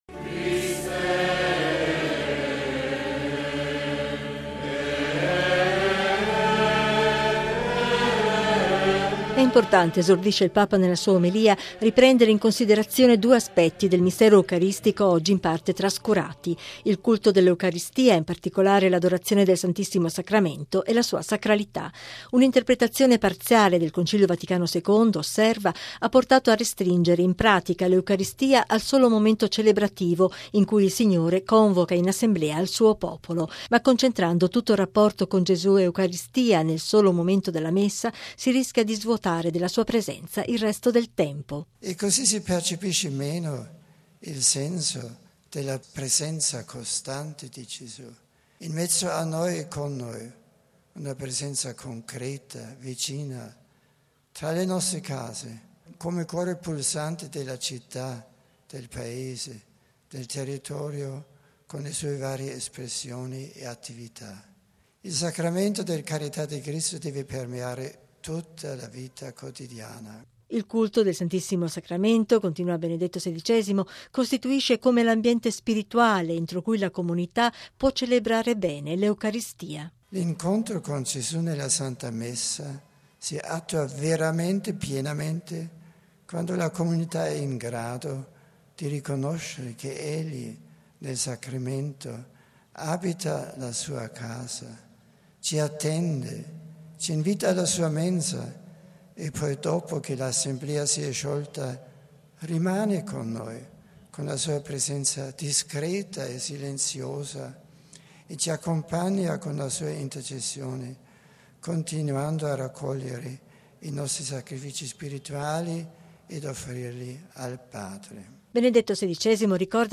◊   Il valore del culto eucaristico e la sacralità dell’Eucaristia: a questi due aspetti ha dedicato la sua omelia Benedetto XVI alla Messa, celebrata ieri sera sul sagrato della Basilica di San Giovanni in Laterano, nella Solennità del Corpus Domini. Al termine, il Papa ha presieduto la Processione eucaristica verso la Basilica di Santa Maria Maggiore, alla presenza di decine di migliaia di fedeli.